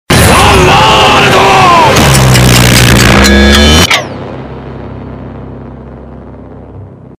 Play, download and share Za Warudo original sound button!!!!